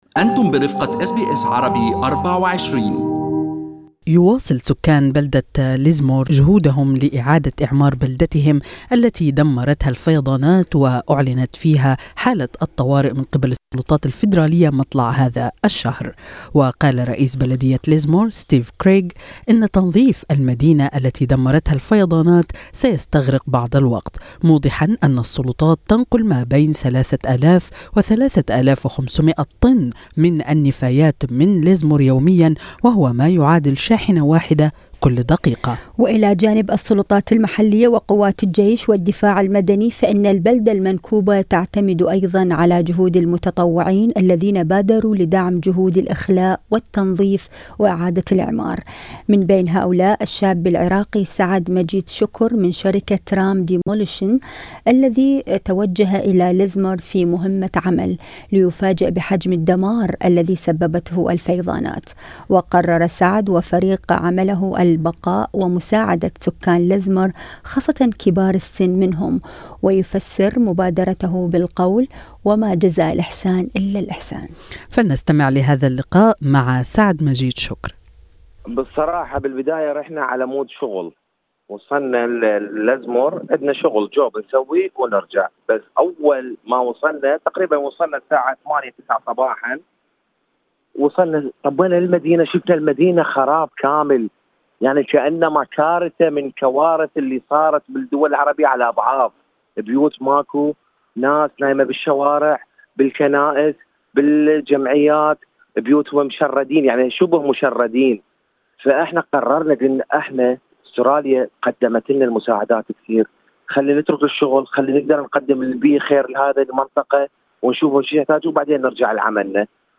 المقابلة الكاملة